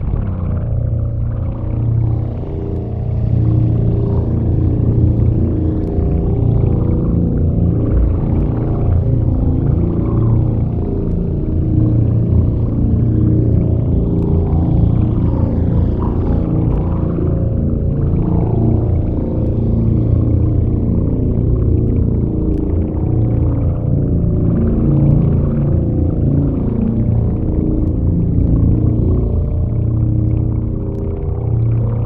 sphere_idle.ogg